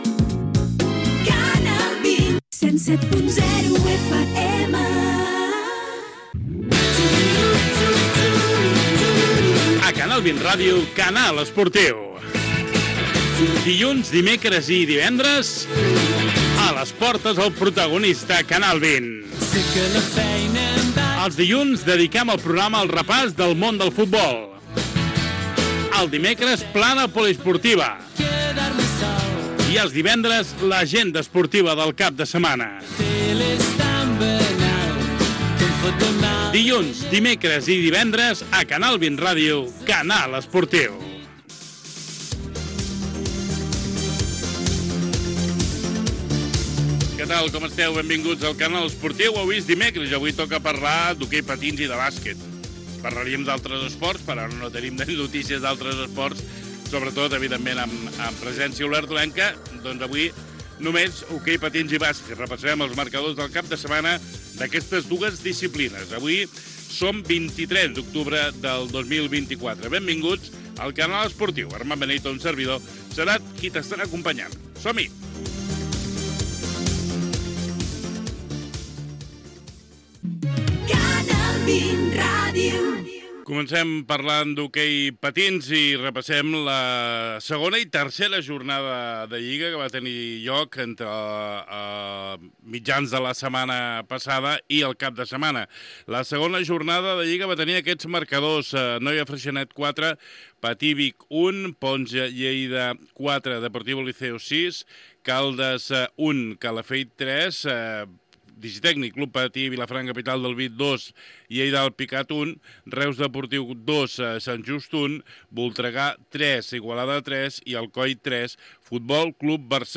Indicatiu de la ràdio, careta del programa, presentació, data, indicatiu i resultats de la lliga masculina d'hoquei patins
Esportiu